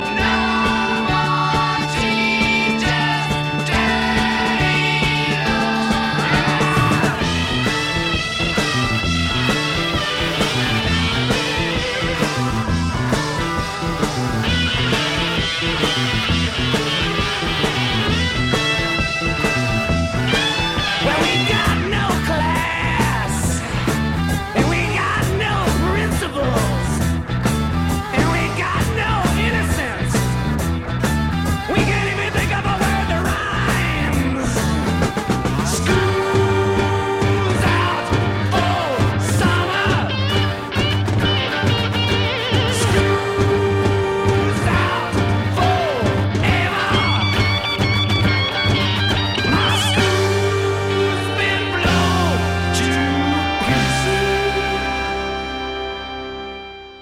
OLD SCHOOL ROCK no. 3